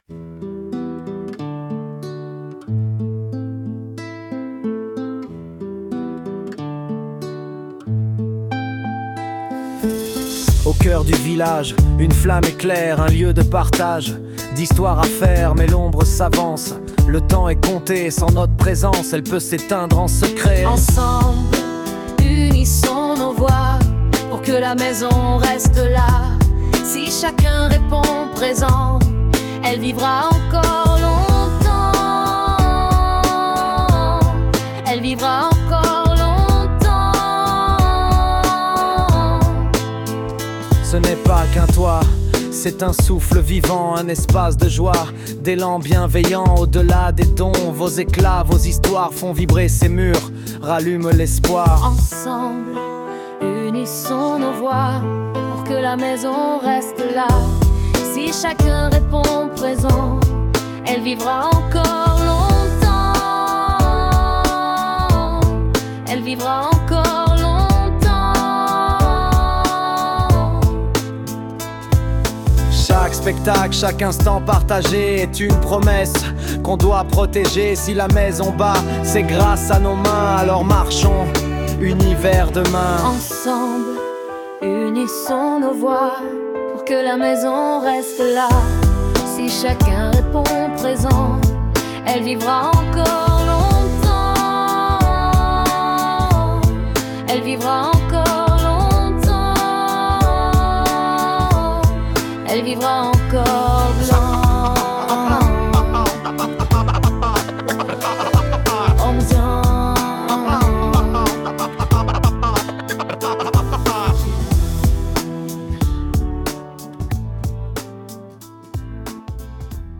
Une chanson d'espoir et d'unité